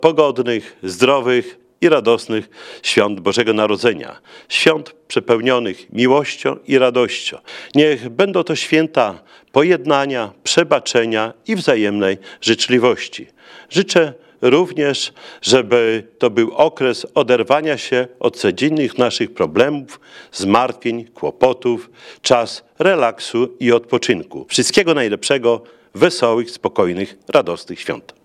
Świąteczne życzenia złożył wszystkim mieszkańcom Suwałk oraz gościom prezydent Czesław Renkiewicz. Włodarz życzył zdrowych, pogodnych i radosnych świąt spędzonych w gronie najbliższych.